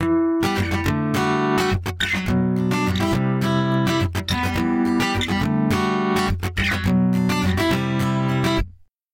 起源 原声吉他
标签： 105 bpm Acoustic Loops Guitar Acoustic Loops 1.54 MB wav Key : E Pro Tools
声道立体声